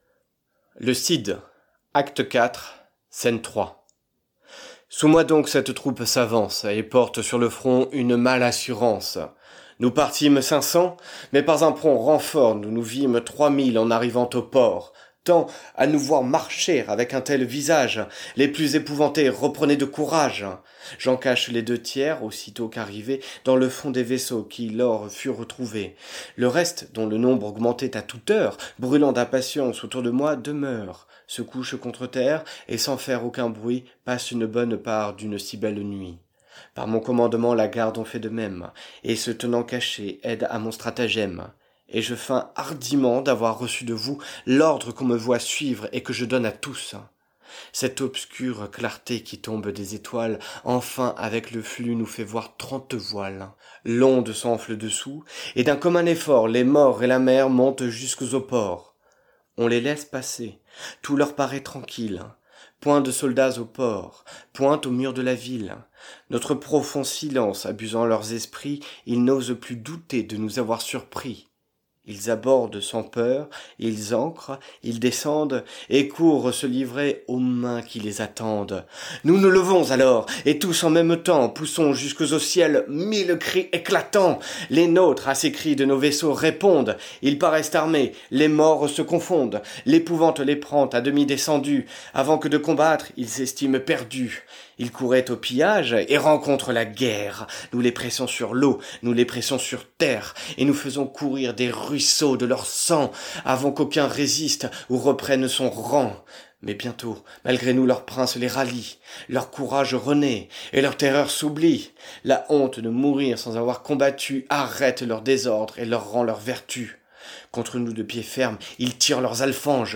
lue par des comédiens.